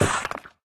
Minecraft Version Minecraft Version snapshot Latest Release | Latest Snapshot snapshot / assets / minecraft / sounds / ui / stonecutter / cut2.ogg Compare With Compare With Latest Release | Latest Snapshot